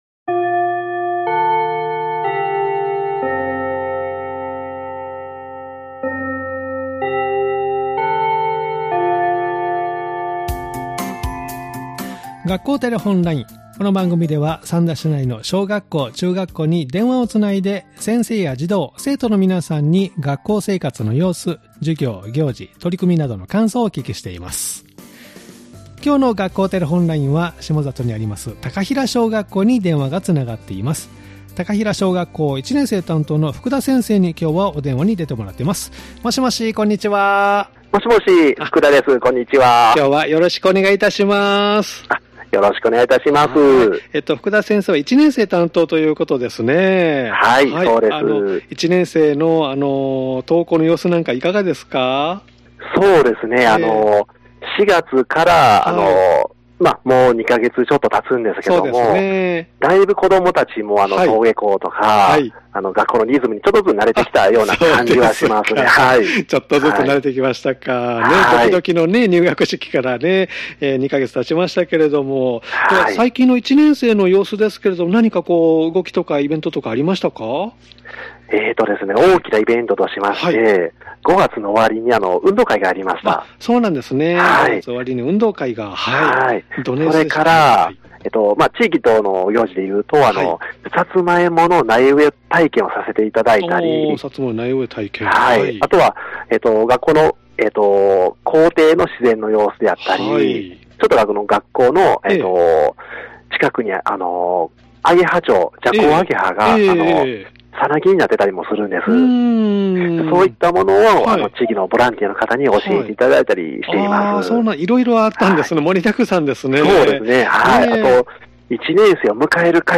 （再生ボタン▶を押すと番組が始まります） 「学校テレフォンライン」では三田市内の小学校、中学校に電話をつないで、先生や児童・生徒の皆さんに、学校生活の様子、授業や行事、取り組みなどの感想をお聞きしています！